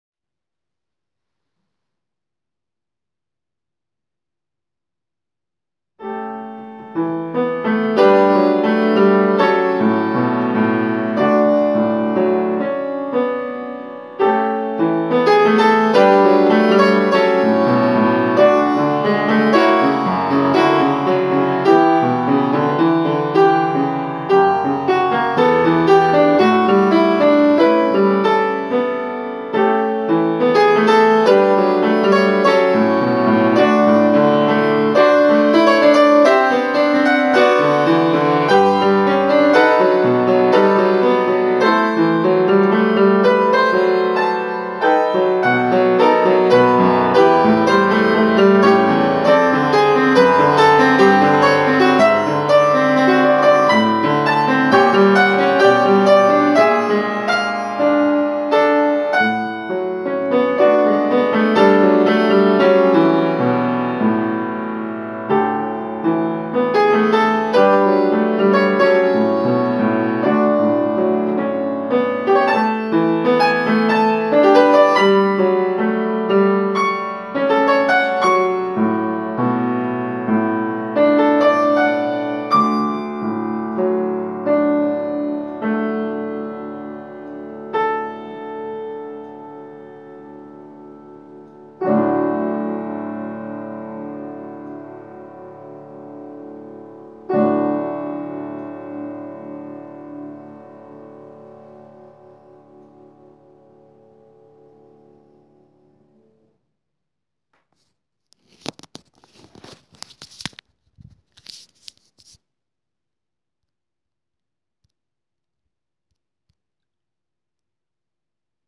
My preparation for the concert 29.4.2018
I reccorded yesterday my intepretation of two Scriabin’s composition’s op.11, no.1 and op.11, no.5.